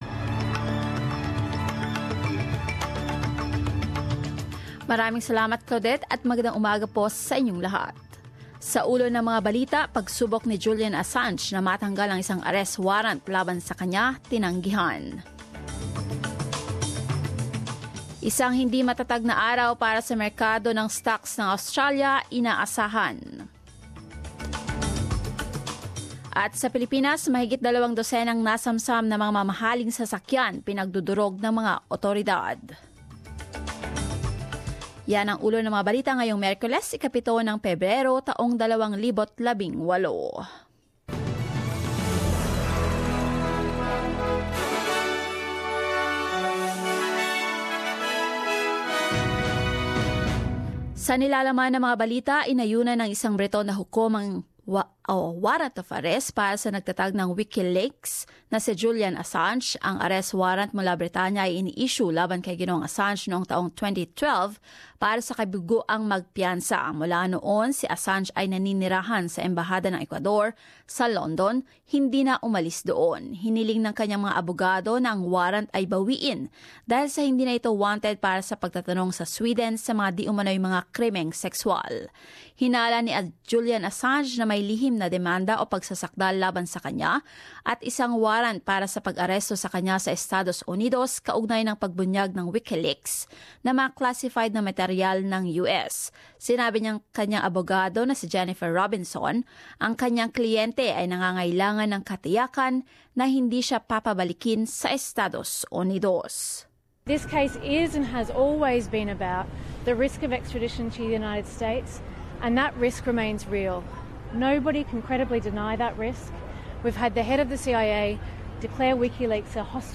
News Bulletin 07 February